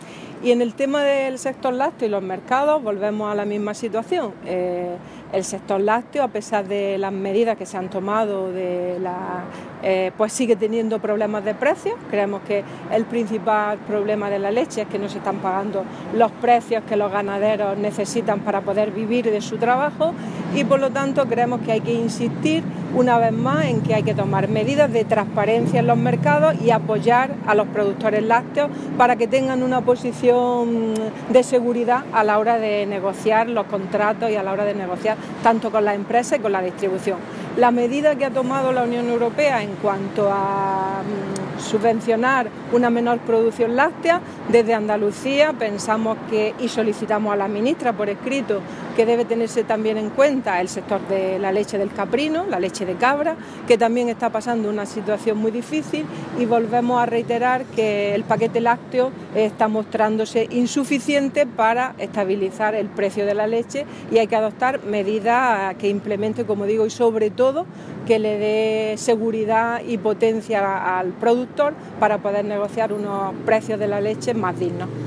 Declaraciones de Carmen Ortiz sobre sector lácteo 1